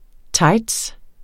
Udtale [ ˈtɑjds ]